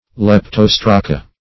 Search Result for " leptostraca" : The Collaborative International Dictionary of English v.0.48: Leptostraca \Lep*tos"tra*ca\ (l[e^]p*t[o^]s"tr[.a]*k[.a]), n. pl.